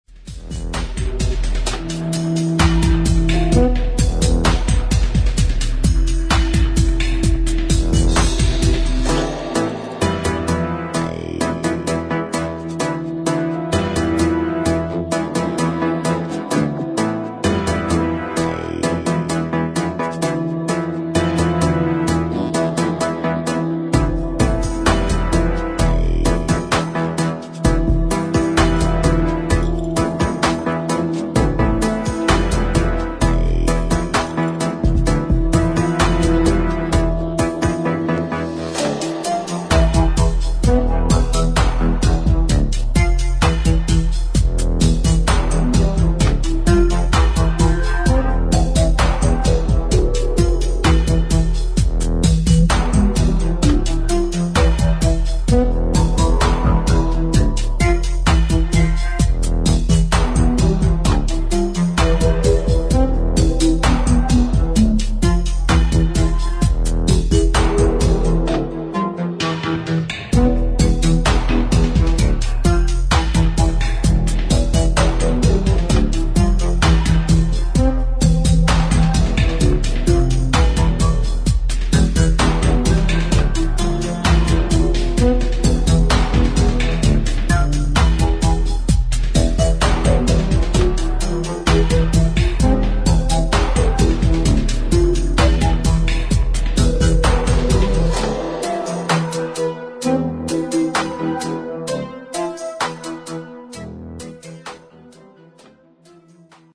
[ DUB / DUBSTEP ]